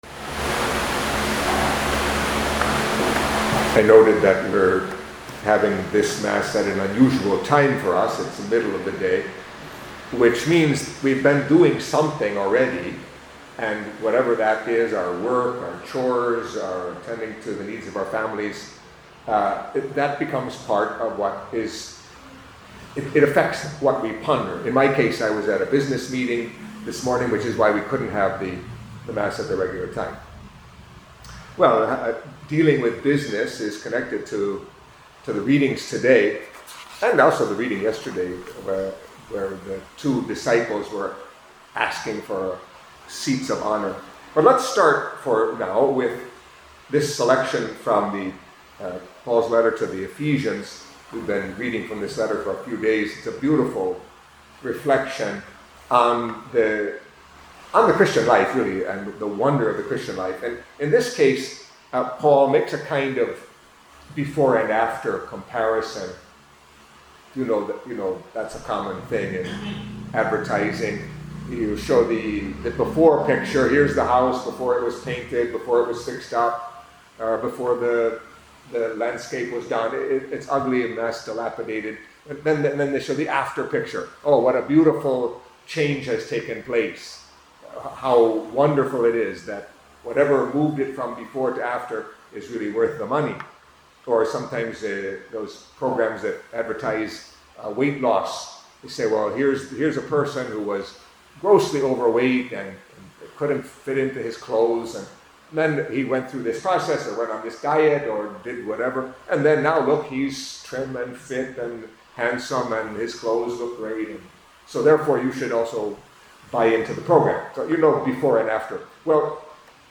Catholic Mass homily for Monday of the Twenty-Ninth Week in Ordinary Time